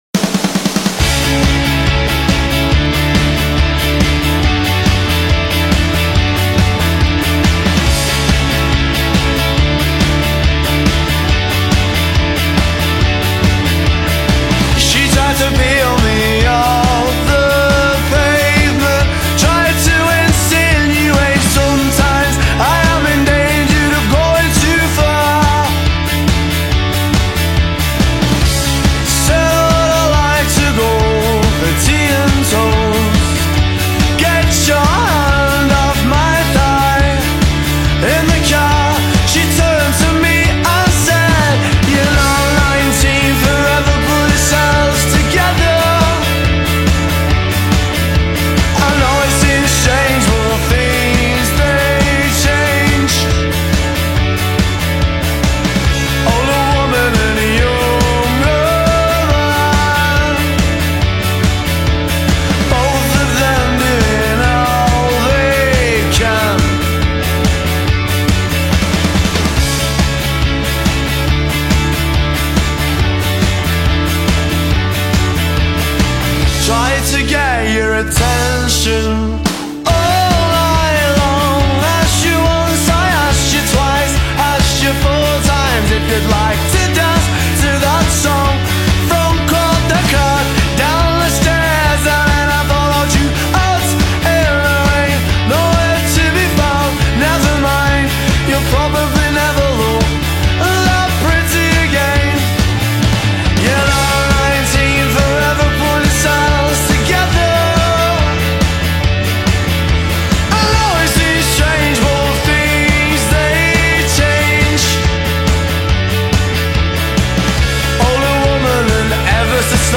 Indie Rock, Britpop